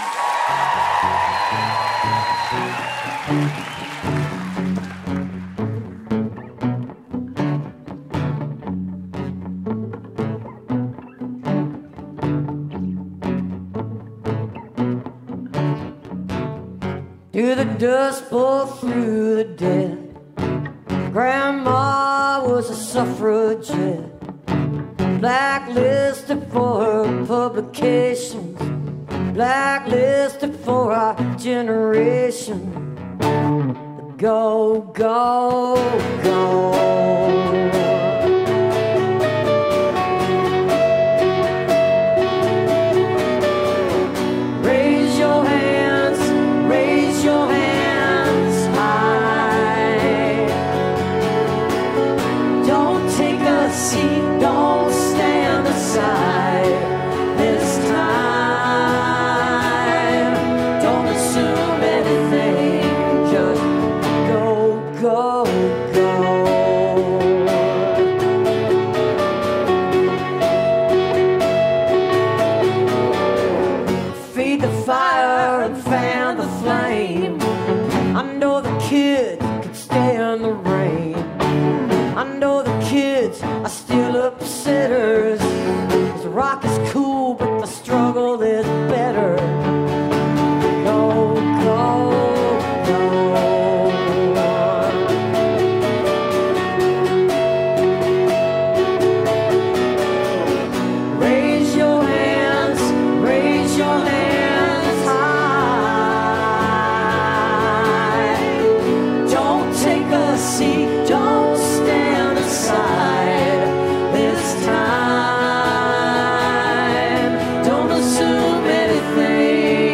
(captured from a youtube live stream)